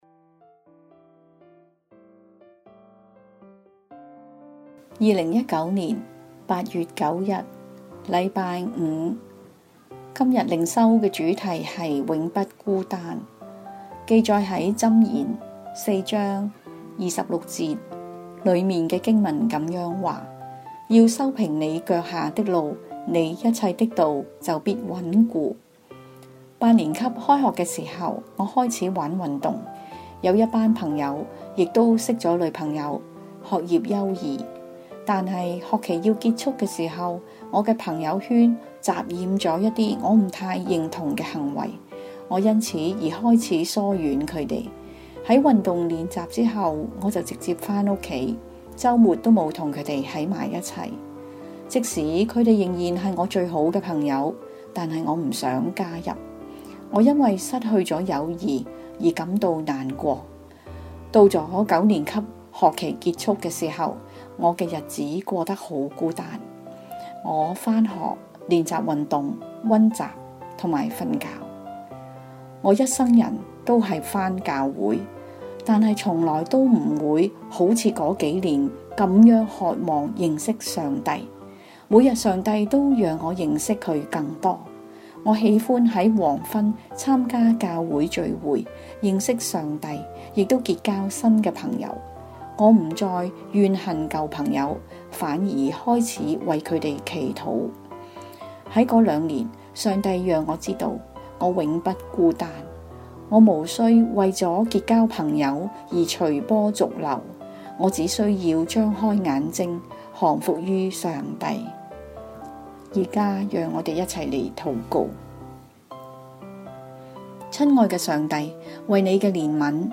永不孤單 六月十六日 禮拜一 讀經 腓立比書 四章四至九節 經文 應當一無掛慮，只要凡事藉着禱告、祈求和感謝，將你們所要的告訴上帝。